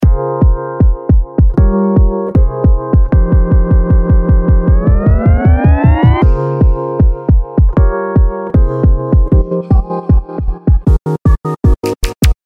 PITCH LOOPER」もROLLと似た機能ですが、ノブを回すとPITCHが変化していきます。
DJ TRANSFORM」は、指定したリズムでダッキングのような効果が得られ、ノブを右に回していくと、DJミキサーのクロスフェーダーを入切するイメージでシャープに切れていくようになります。